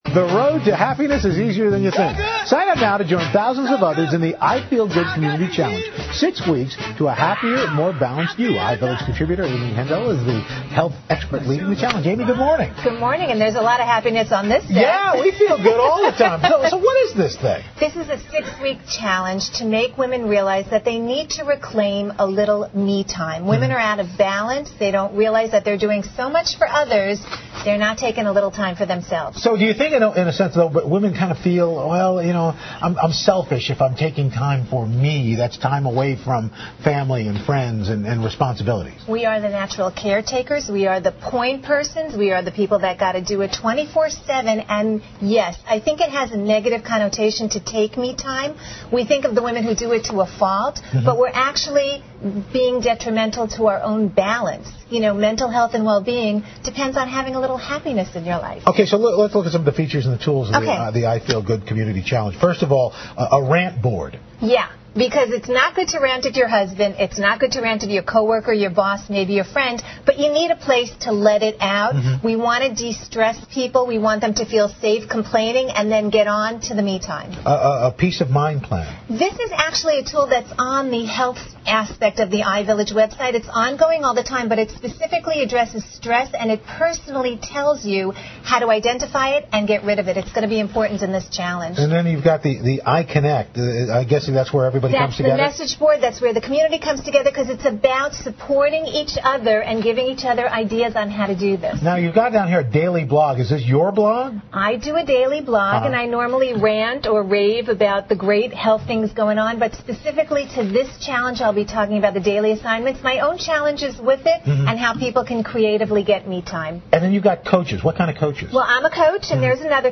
访谈录 Interview 2007-05-12&14, 如何获得幸福？ 听力文件下载—在线英语听力室